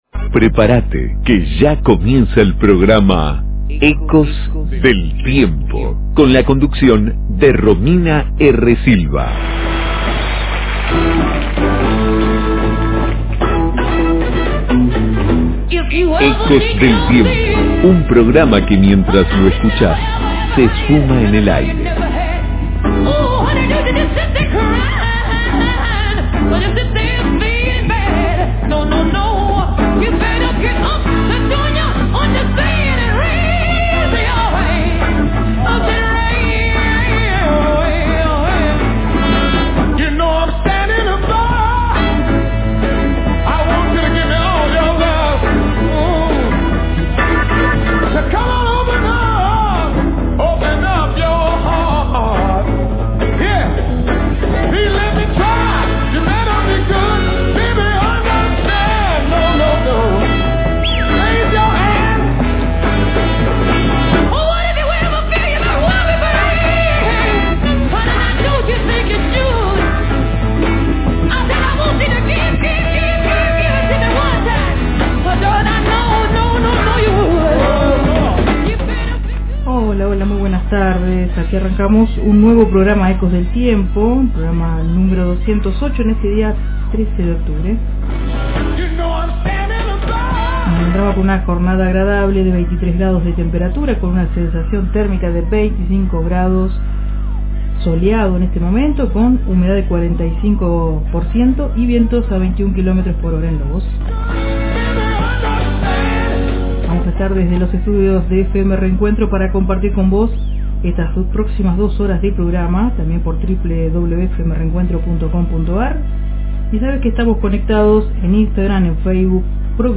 Entrevista
Conferencia de prensa